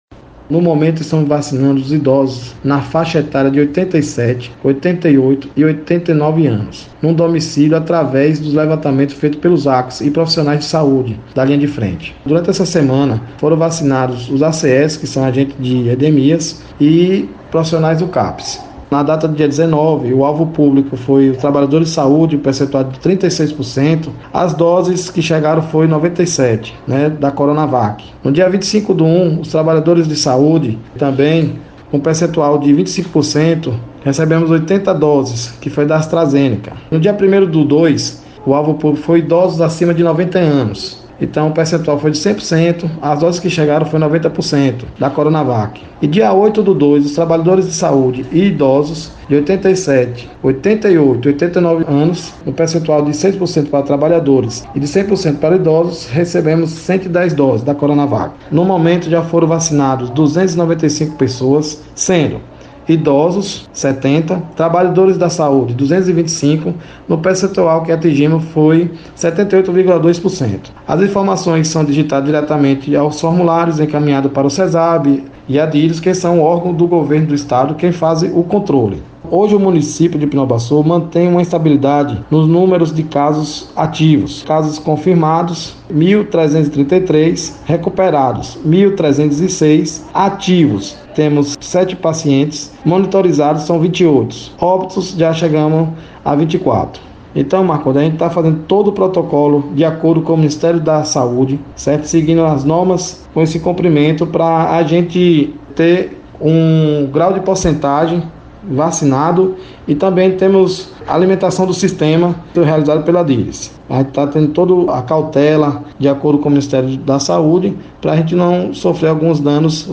Alessandro Reis secretário de saúde de Pindobaçu – vacinação contra a covid